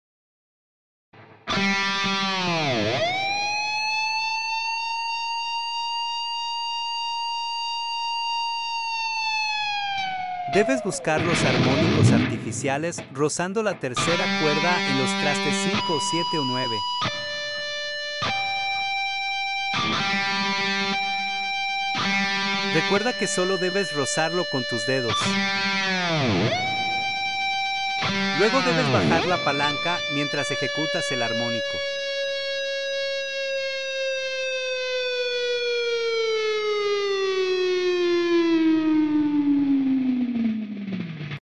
Cómo hacer un Dive Nomb en guitarra eléctrica?
- Debes buscar los armónicos artificiales en la 3ra cuerda en los espacios 5, 8 y 9 que son los más fáciles de lograrlo.
- Baja la "palanca" o trémolo mientras ejecutas el armónico artificial.